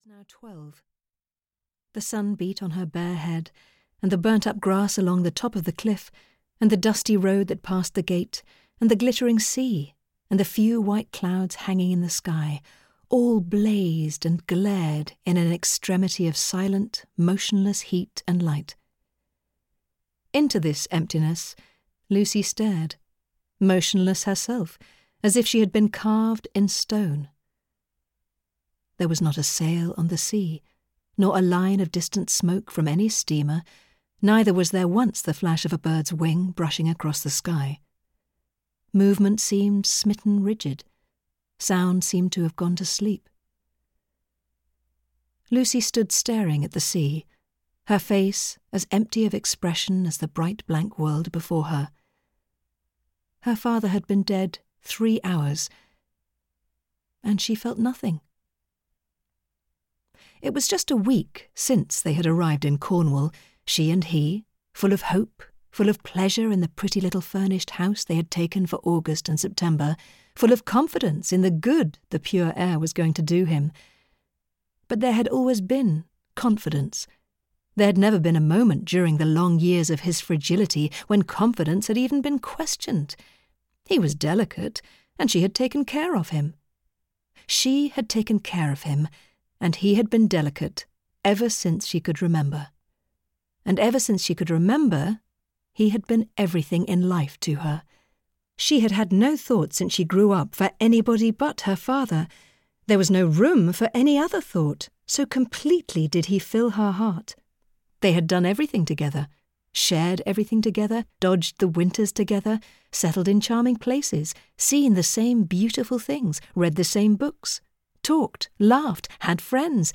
Vera (EN) audiokniha
Ukázka z knihy